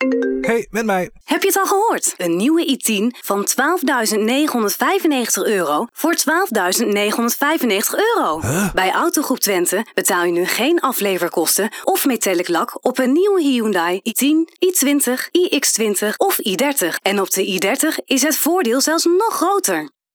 voice-over-demo-autogroep-twente.wav